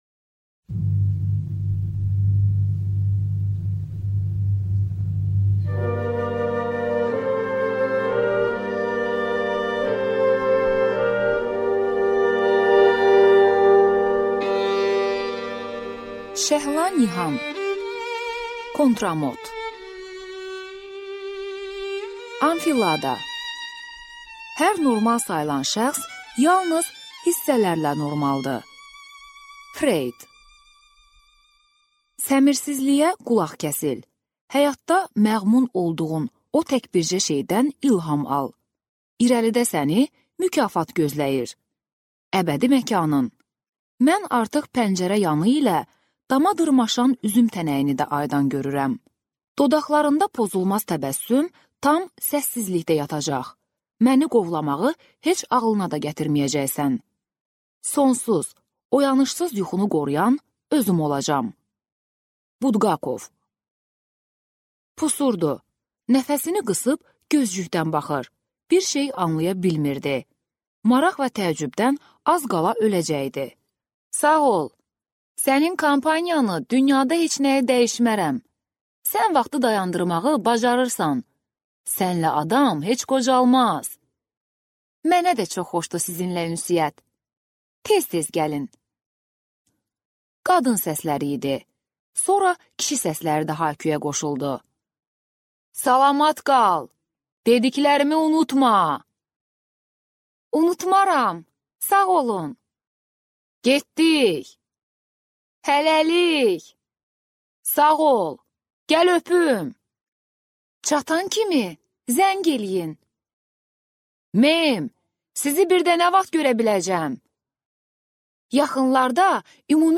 Аудиокнига Kontramot | Библиотека аудиокниг